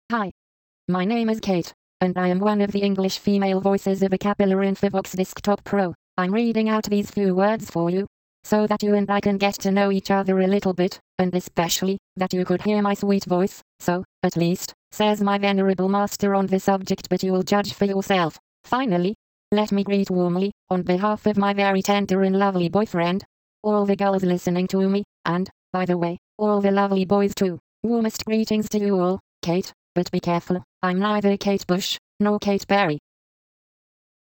Texte de démonstration lu par Kate, voix féminine anglaise d'Acapela Infovox Desktop Pro
Écouter la démonstration de Kate, voix féminine anglaise d'Acapela Infovox Desktop Pro